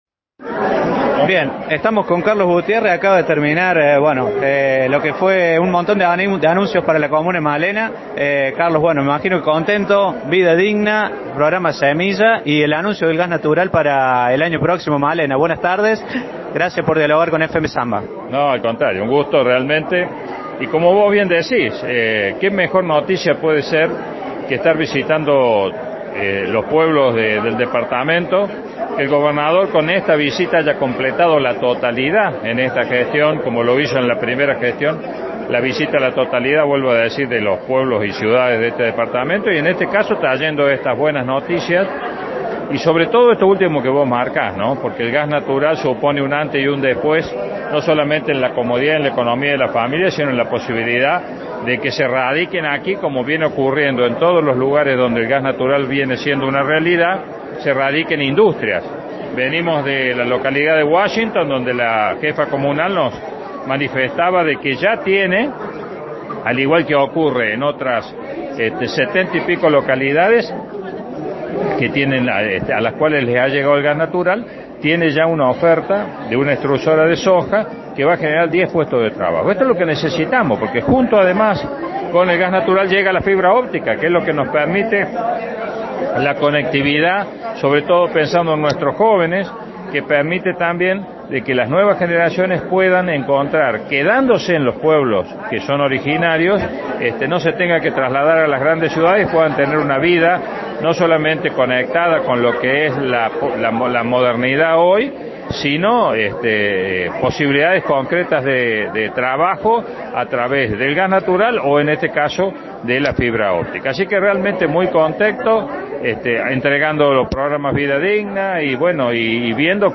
Acompañaron al Gobernador los Legisladores Carlos Gutierrez y Adriana Oviedo.
Escuchamos audio con nota a Carlos Gutierrez: